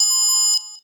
beep.ogg